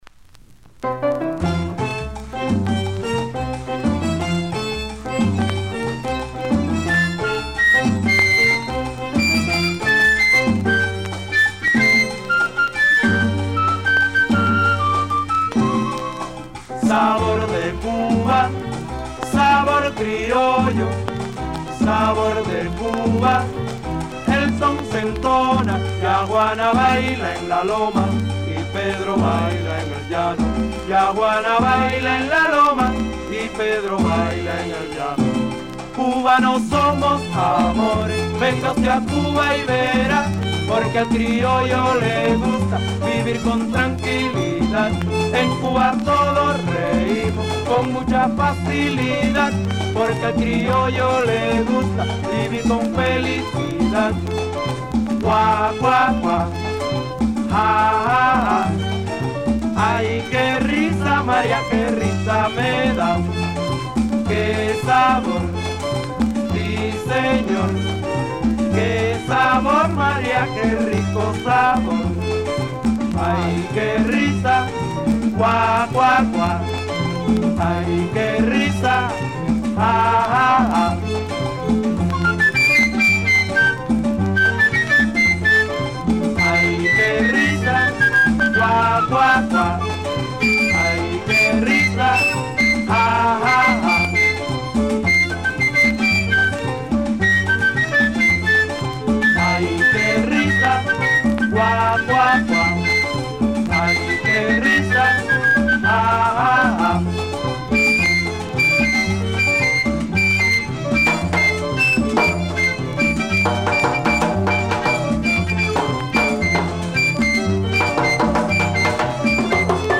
ルンバ、ダンソン、マンボ、ヤンブー、ワラーチャ、パチャンガ、プレーナ、モザンビーケ、ボレロ
あらゆるラテン・エッセンスを融合した楽曲を得意とする。